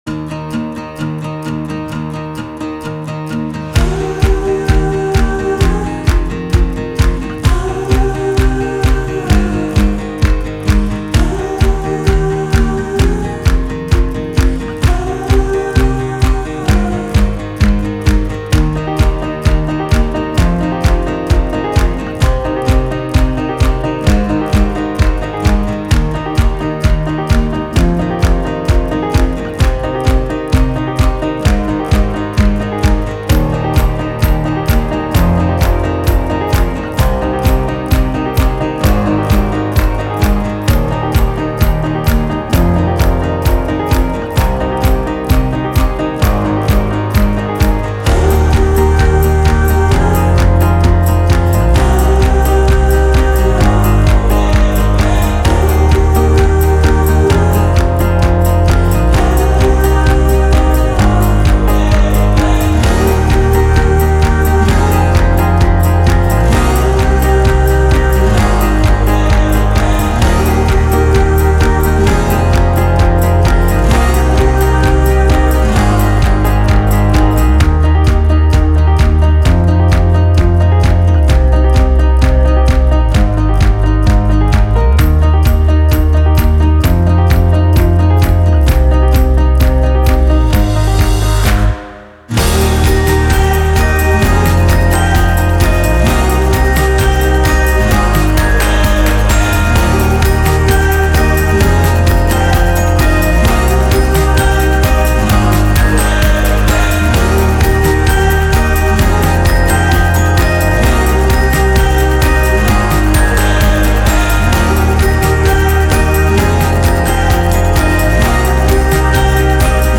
MOTIVATIONAL UPBEAT INDIE FOLK
Acoustic / Inspiring / Hopeful / Uplifting